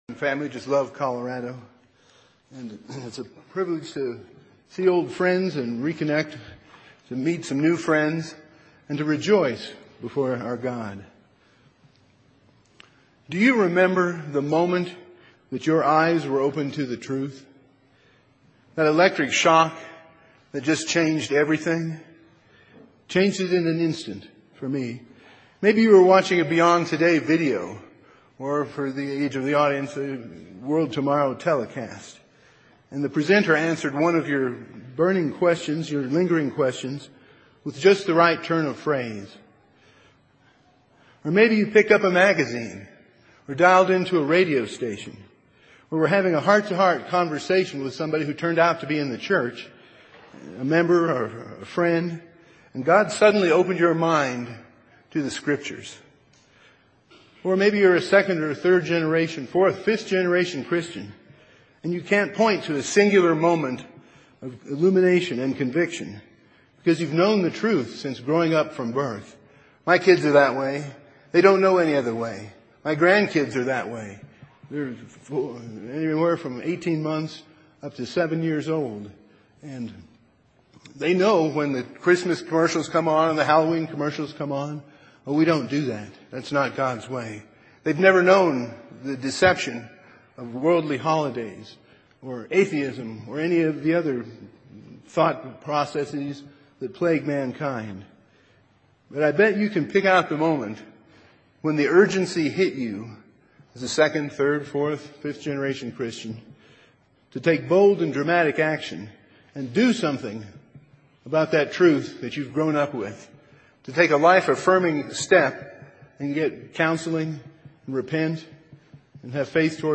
This sermon was given at the Estes Park, Colorado 2022 Feast site.